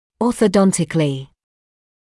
[ˌɔːθə’dɔntɪklɪ][ˌоːсэ’донтикли]ортодонтически; ортодонтическими средствами; с помощью ортодонтии
orthodontically.mp3